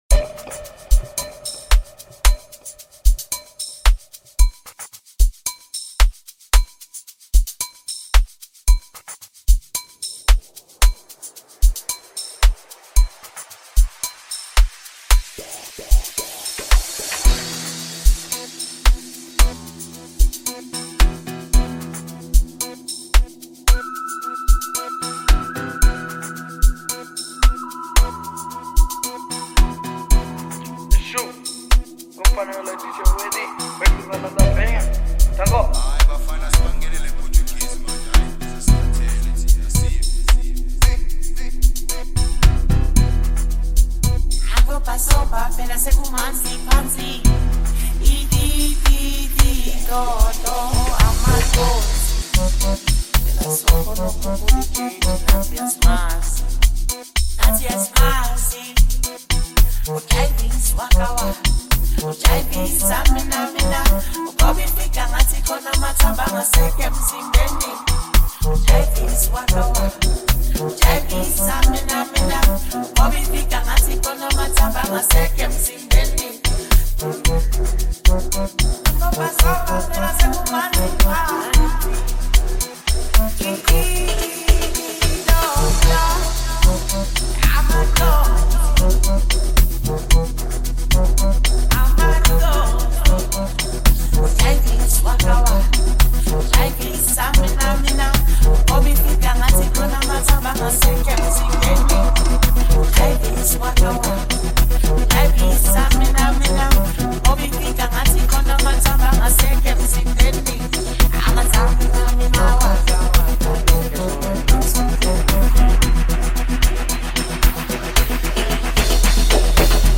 Multi talented South African song producer